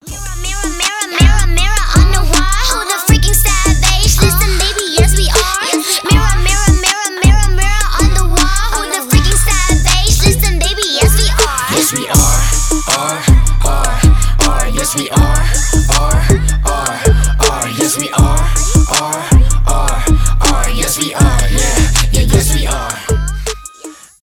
рэп
дуэт
trap